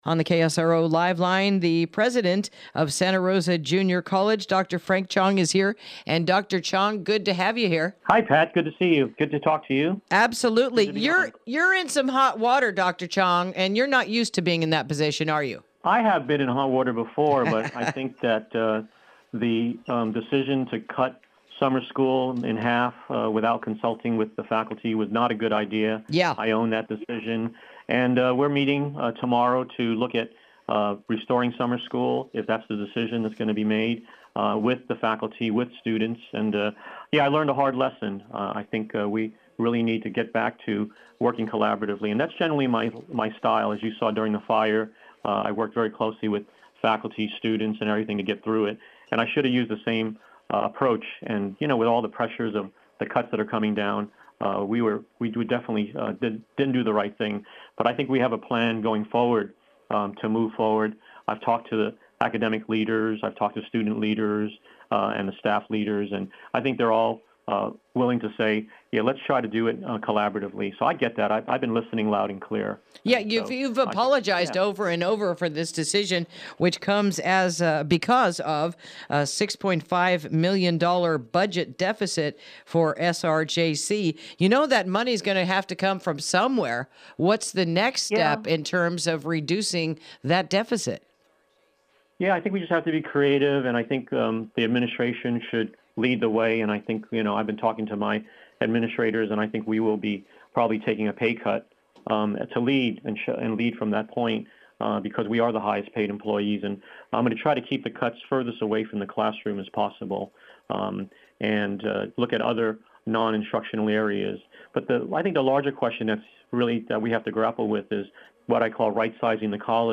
Interview: The Controversy Over the Cancellation of Summer Classes at SRJC